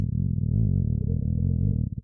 На этой странице собраны разнообразные звуки голода: от тихого урчания до громкого бурчания пустого желудка.
Реалистичные звуки пищеварительной системы, которые точно передают ощущение голода.
Голодный звук человека